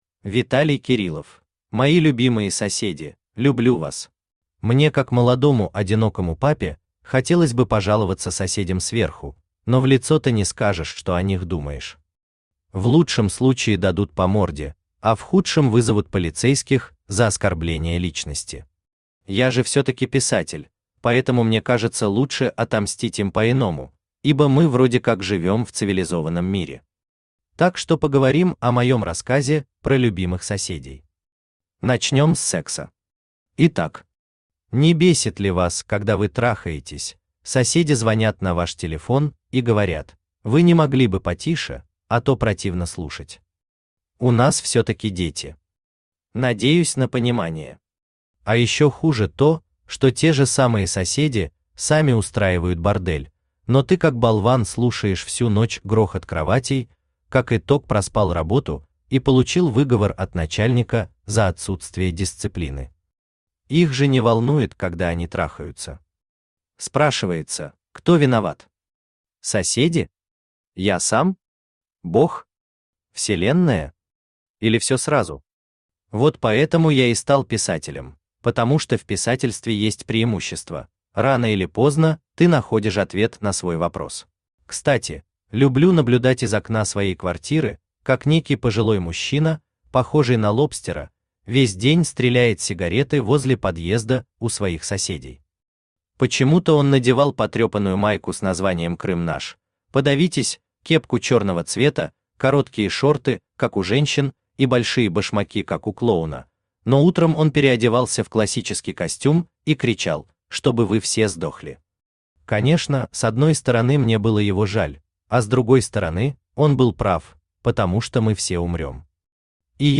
Аудиокнига Мои любимые соседи, люблю вас!
Автор Виталий Александрович Кириллов Читает аудиокнигу Авточтец ЛитРес.